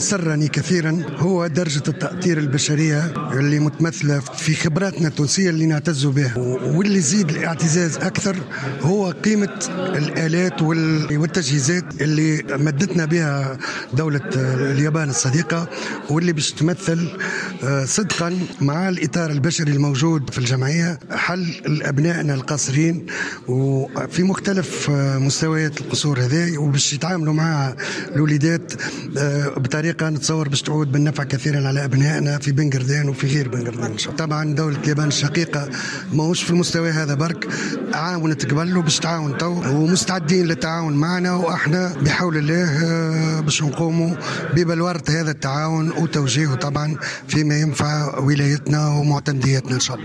والي مدنين سعيد بن زايد يتحدث لمراسلنا
wali-mednine-ben-guerdane.mp3